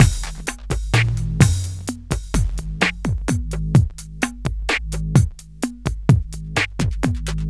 Downtempo 08.wav